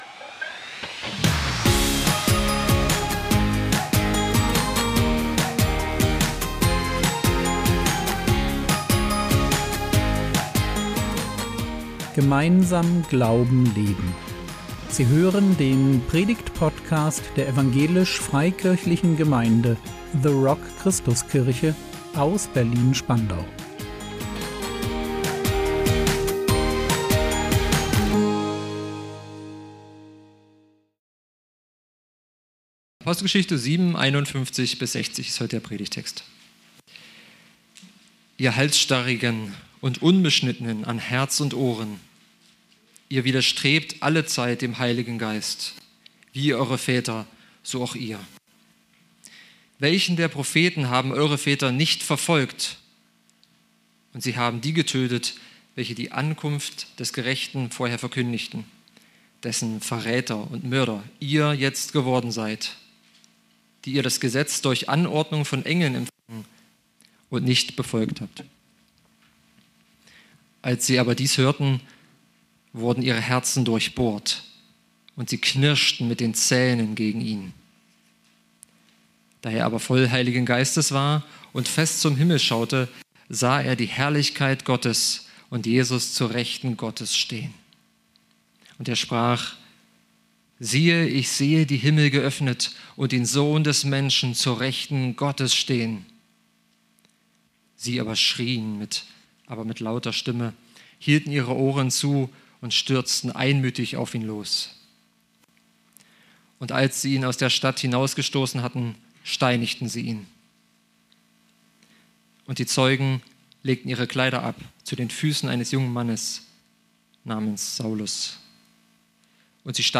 Identität unter Steinen | 22.02.2026 ~ Predigt Podcast der EFG The Rock Christuskirche Berlin Podcast